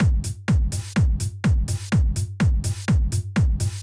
Drumloop 125bpm 08-C.wav